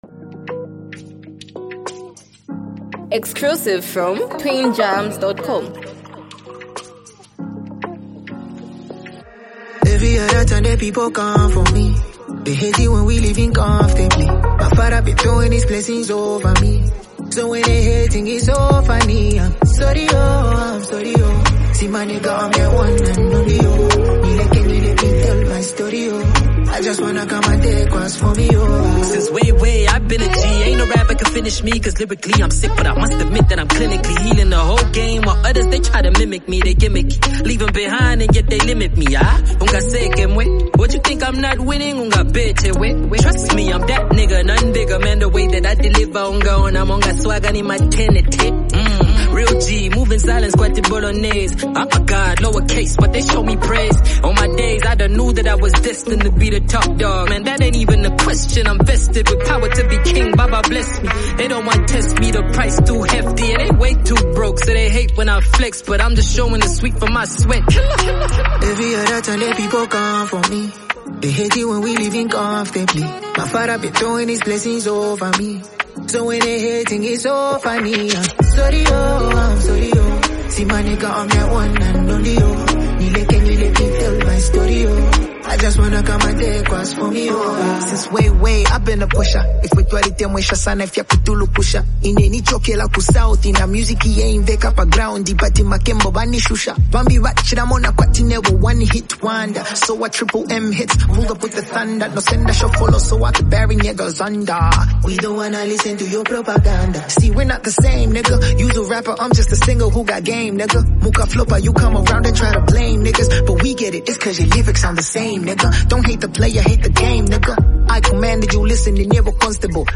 raw intensity and street-flavored delivery